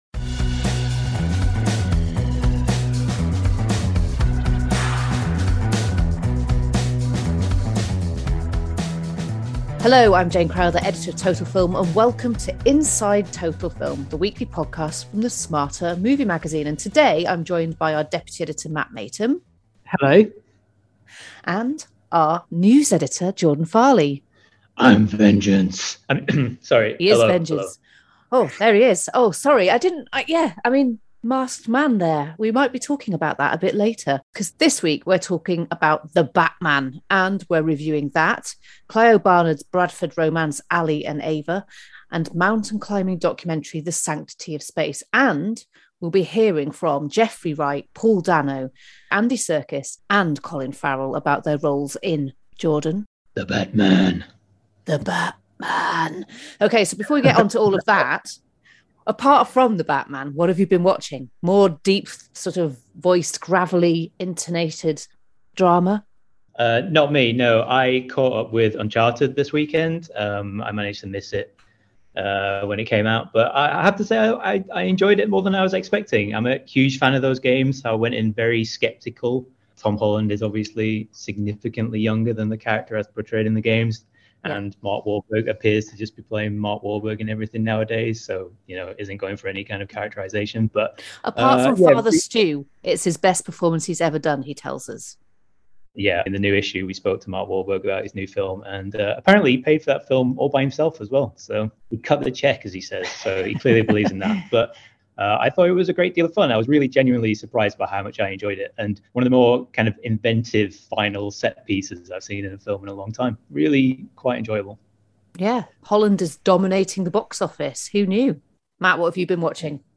Review of The Batman including exclusive interviews with cast members Colin Farrell, Andy Serkis, Jeffrey Wright and Paul Dano